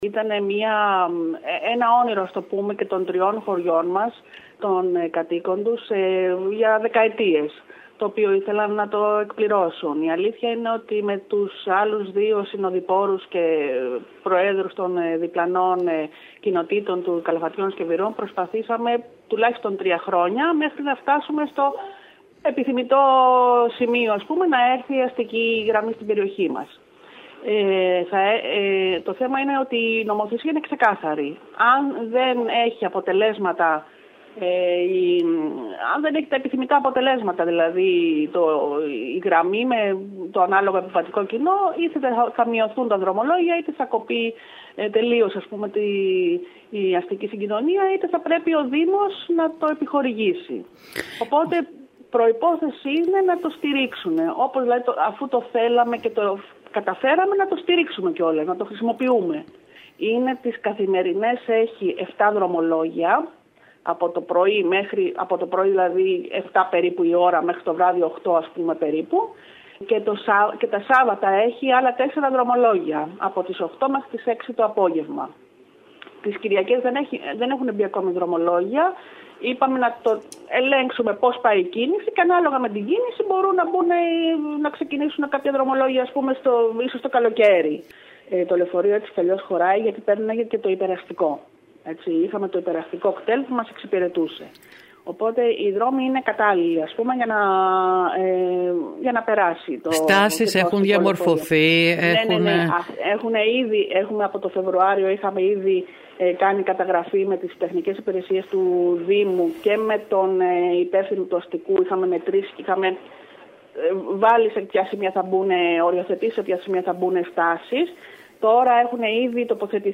Τα δρομολόγια της νέας γραμμής θα ξεκινήσουν τη Δευτέρα 02 Σεπτεμβρίου 2019 και θα έχουν ως αφετηρία την οδό Μητροπολίτου Μεθοδίου και τέρμα τους Βαρυπατάδες. Ακούμε την πρόεδρο του Τοπικού συμβουλίου Βαρυπατάδων Ρούλα Μαργαρίτου.